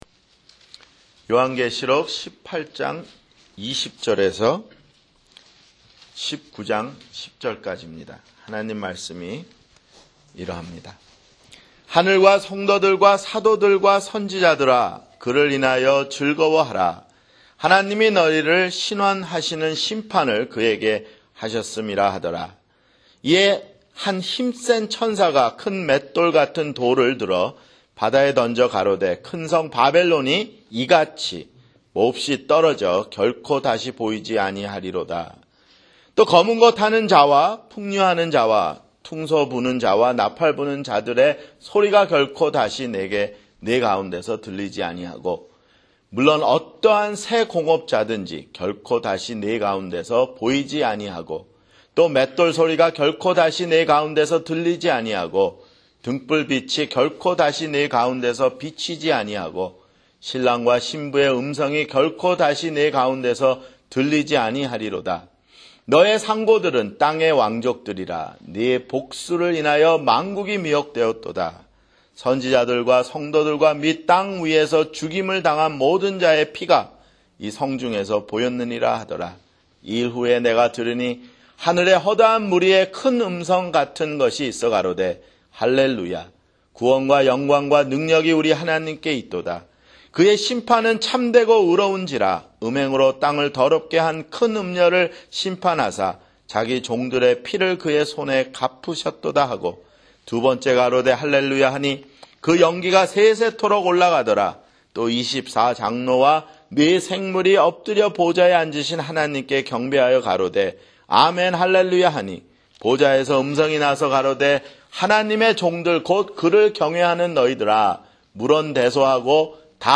[주일설교] 요한계시록 (71)